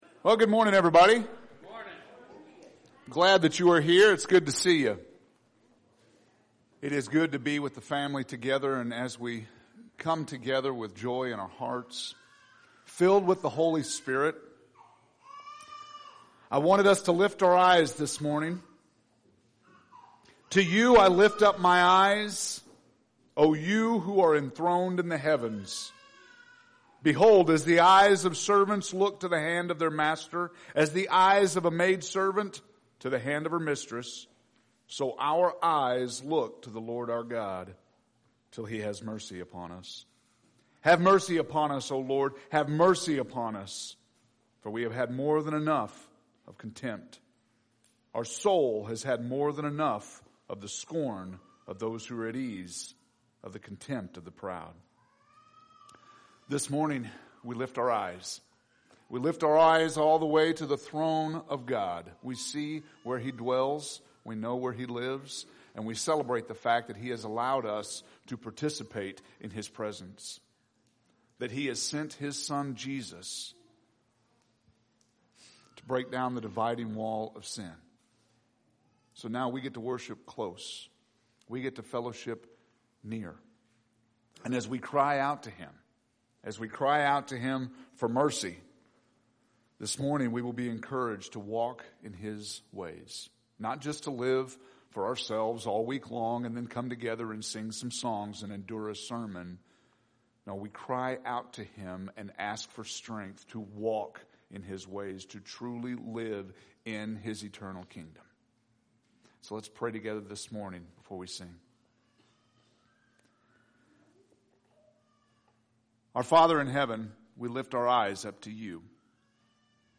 Sermon – Page 28 – Sermons